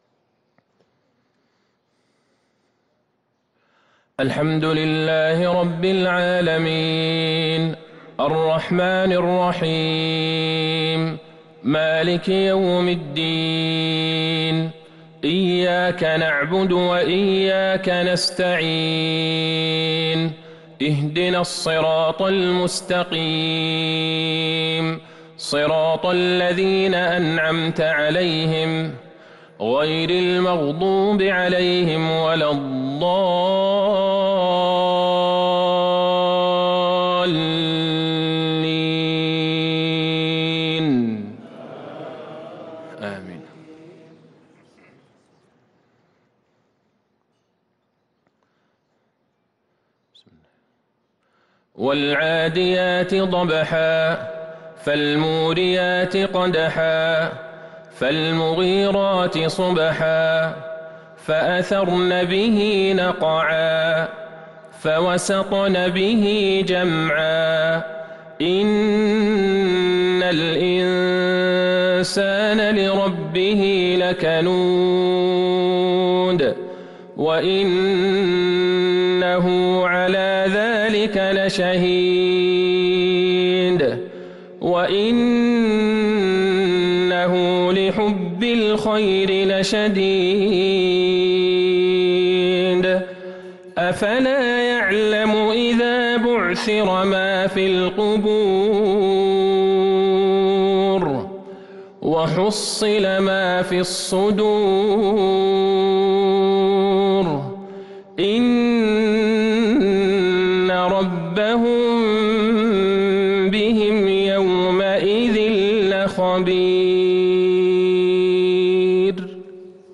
مغرب الثلاثاء ٧ رجب ١٤٤٣هـ | العاديات و قريش | Maghrib prayer from Surah Al-A’adiyat & Qurayish 8-2-2022 > 1443 🕌 > الفروض - تلاوات الحرمين